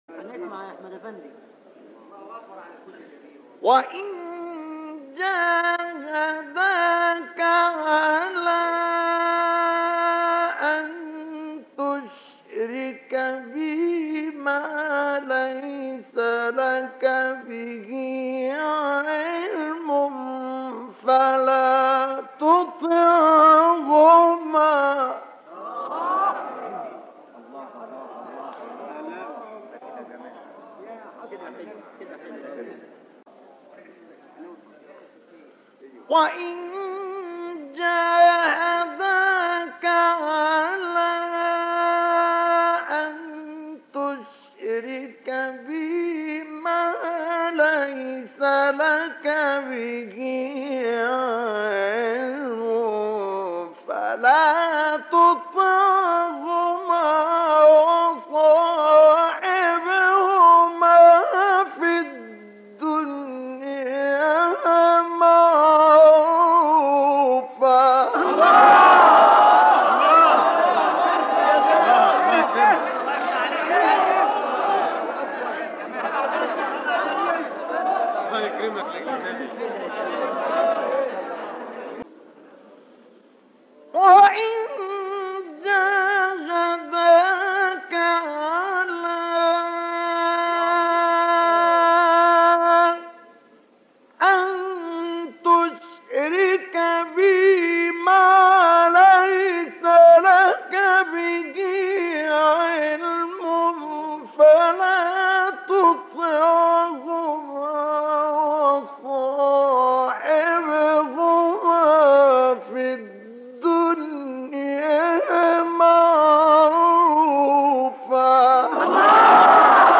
شبکه اجتماعی: مقاطع صوتی از تلاوت قاریان برجسته مصری ارائه می‌شود.
مقطعی از مصطفی اسماعیل/ سوره لقمان آیه 15 ،اسکندریه 1962)